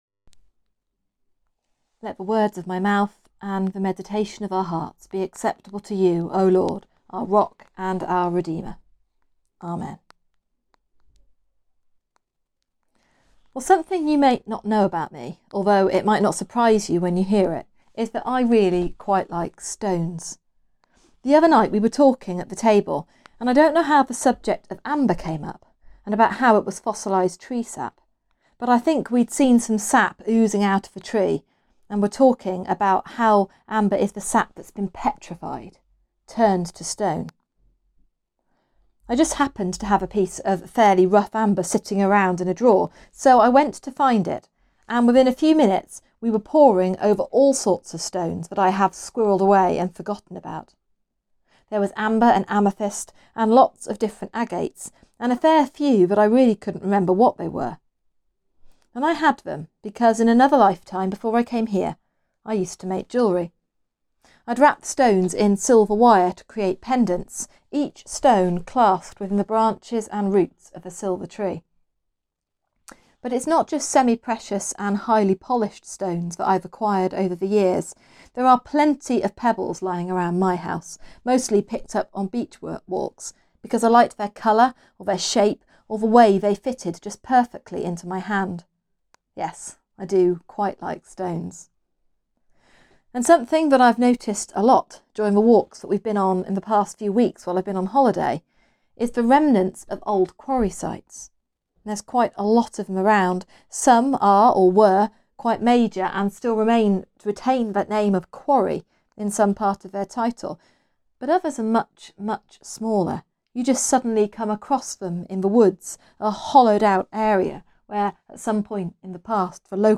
Sermon: Solid Ground Beneath our Feet | St Paul + St Stephen Gloucester
Sermon-23.8.20.mp3